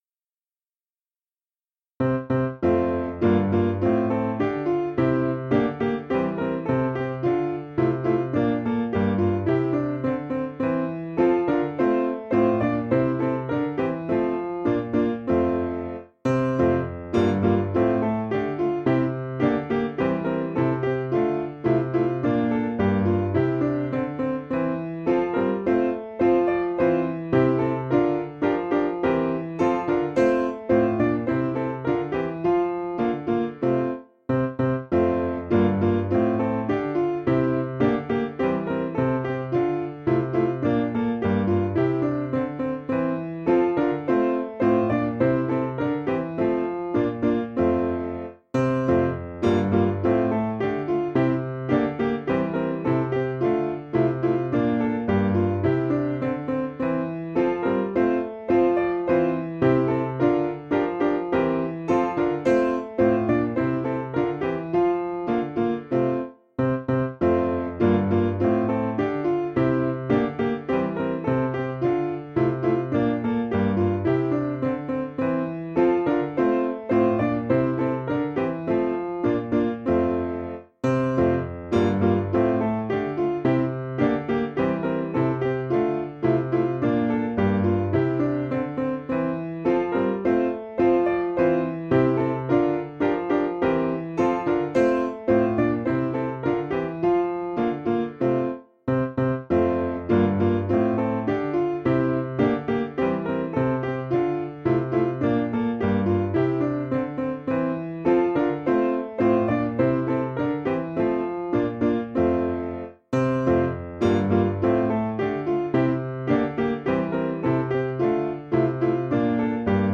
Key: G Major Source: English traditional